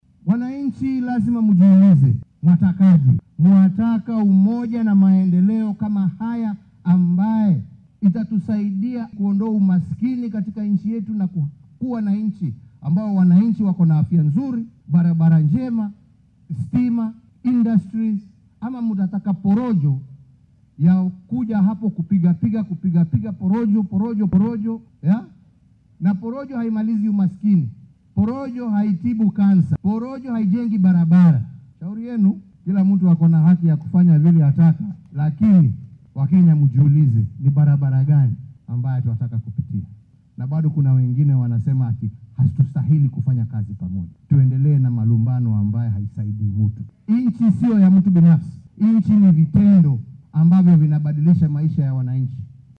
Madaxweynaha dalka Uhuru Kenyatta ayaa hoggaamiyaasha siyaasadeed ku boorriyay inay mudnaanta koowaad siiyaan ka shaqeynta danaha shacabka oo aynan ka hor marin kuwa iyaga gaarka u ah. Mr. Kenyatta ayaa xusay inay lagama maarmaan tahay in mas’uuliyiinta ay iska ilaaliyaan kala qaybinta bulshada iyo adeegsiga aflagaaddada. Xilli uu kulan la qaatay madaxda ismaamullada gobolka xeebta ayuu madaxweyne Kenyatta ku celceliyay in haddii aynan midnimo jirin ay adkaanayso in wadanka uu horumar wax ku ool ah ku tallaabsado.